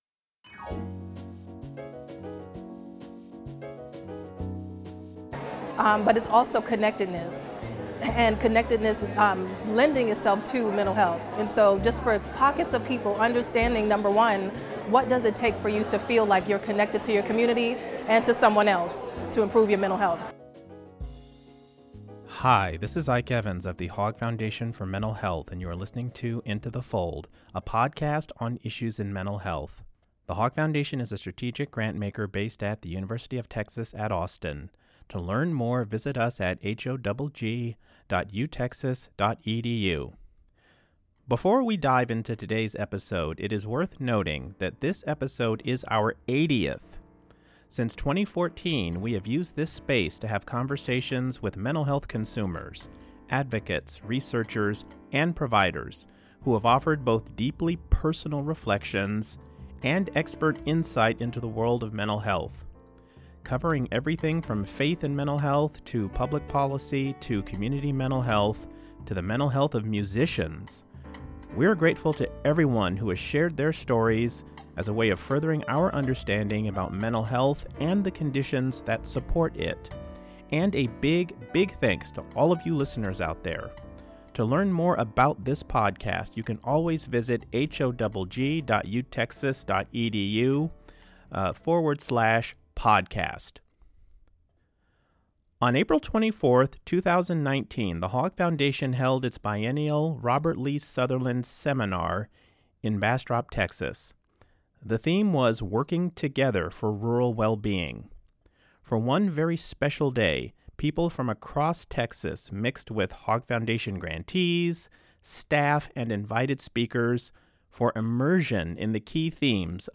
For this special episode, we caught up with some attendees to get their reflections about the topics discussed at the event. Two themes emerged: the need to strengthen connections and move upstream.